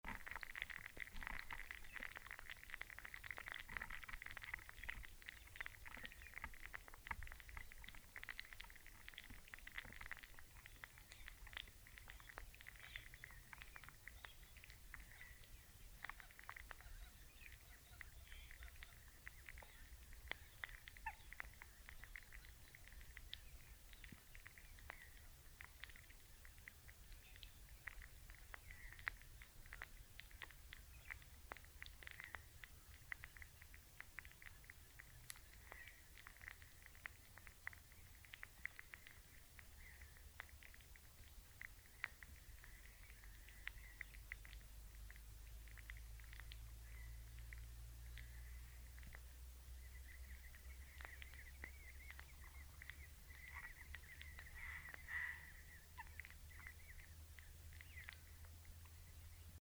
The following audio samples were recorded the day after the soundwalk using the same equipment and locations.
There were also more birds present when I returned alone, picking up the sound of swamp hens and kookaburras through the geofón and hydrophone.
Audio sample of decaying couch grass in the lagoon with a hydrophone, Tiger Bay Wetland
Couchgrassinwater_hydro_TigerBay02_EDITED.mp3